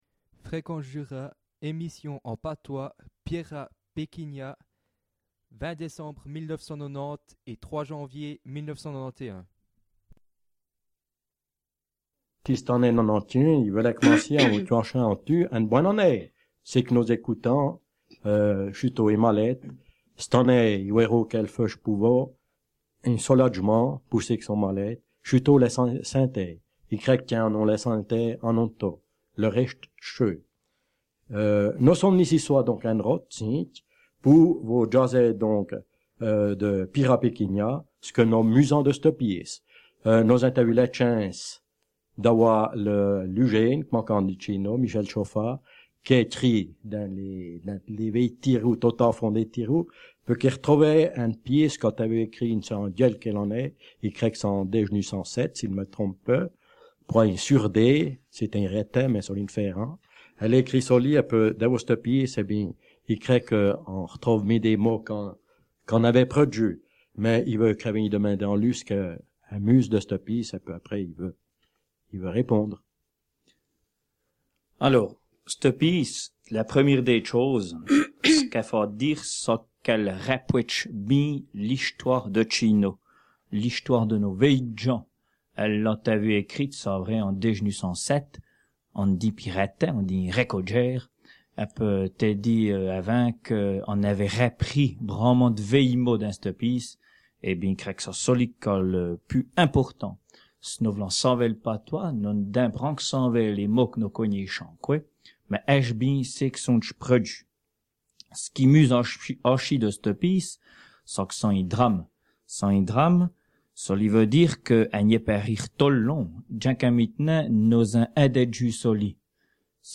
Fréquence Jura - Récit en patois